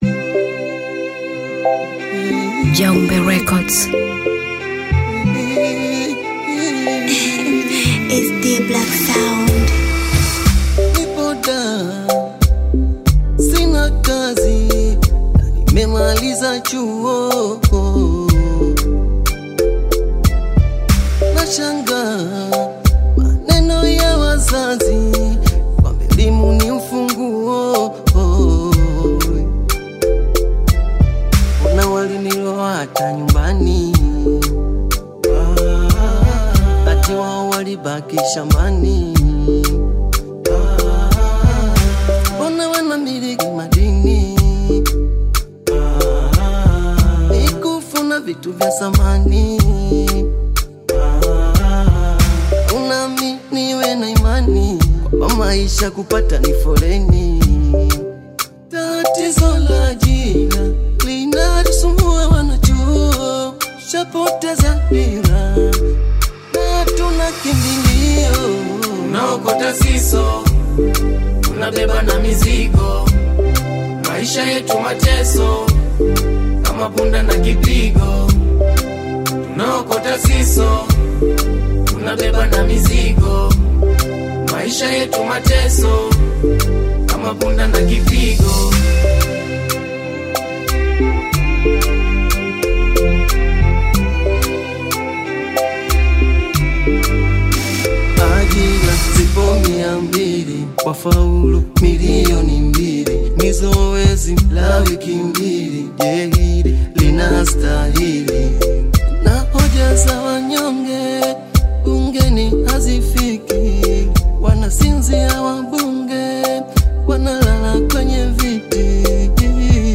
AudioBongo flava
is a lively Bongo Flava/Hip-Hop collaboration